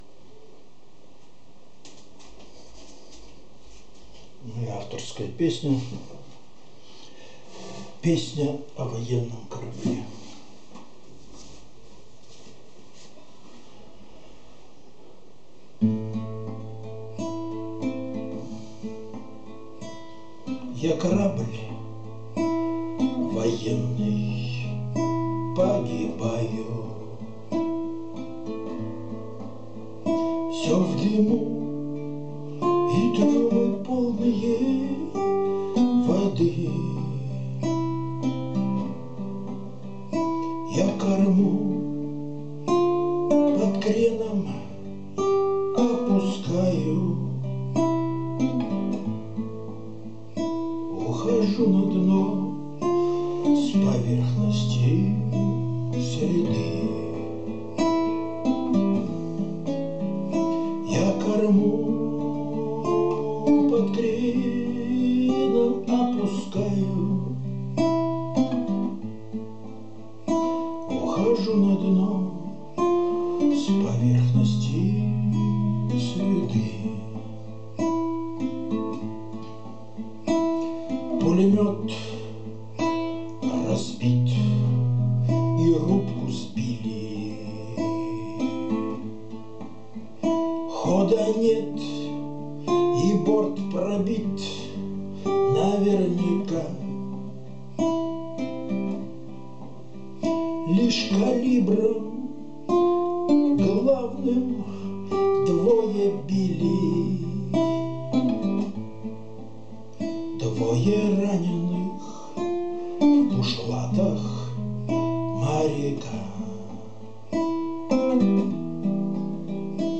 Жанр: Авторская